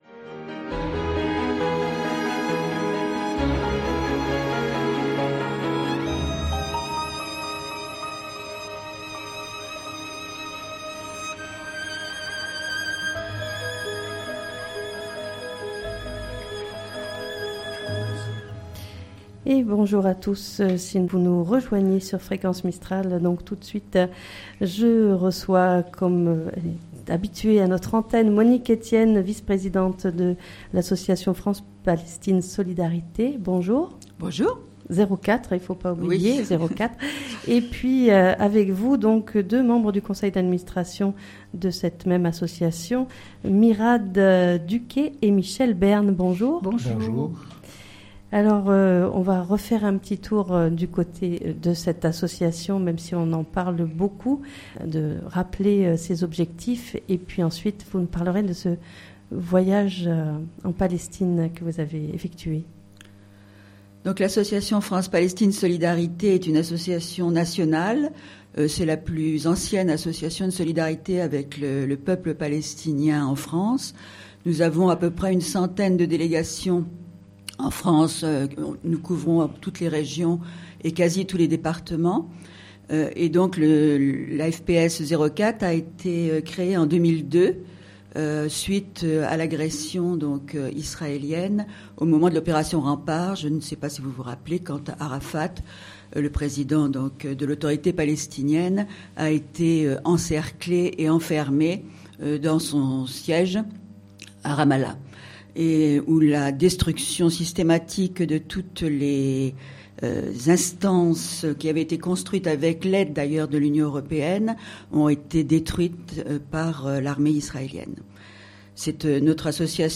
Pour nous en parler, 3 invités ce lundi en direct sur notre antenne de Fréquence Mistral Digne.